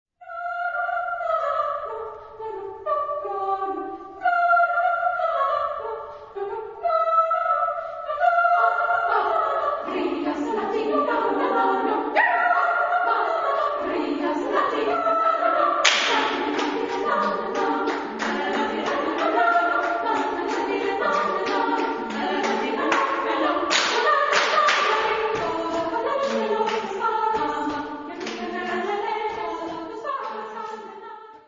Texto en: español ; Onomatopeya
Género/Estilo/Forma: Profano ; Danza
Carácter de la pieza : rítmico ; sincopado